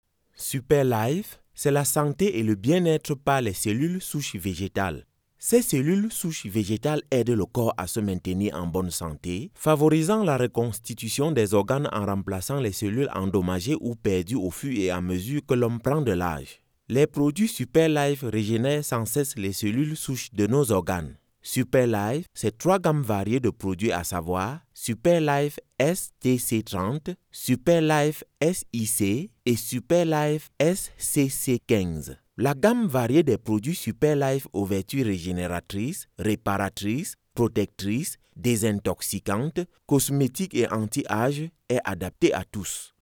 French voice over, African French, Skype and Zoom, French Narration, French E-learning, French Commercial, French Documentary.
Sprechprobe: Industrie (Muttersprache):
I have a home studio to do client's work quick as possible.